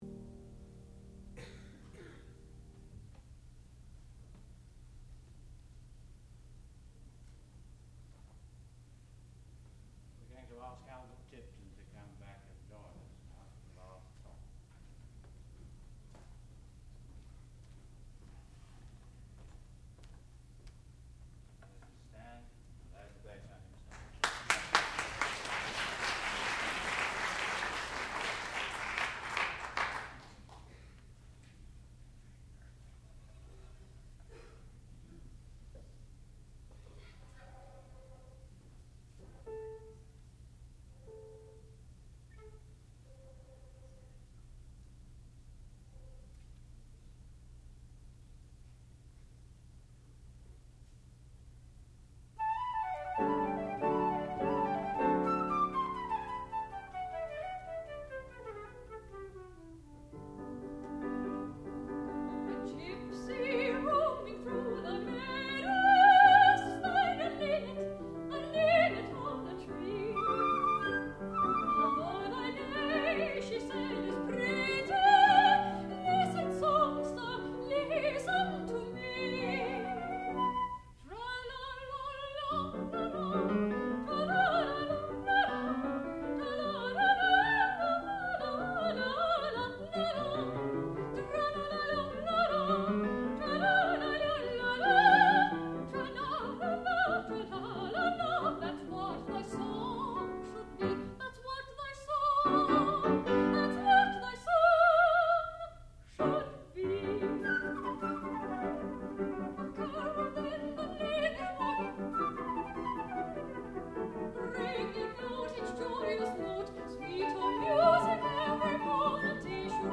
sound recording-musical
classical music
piano
soprano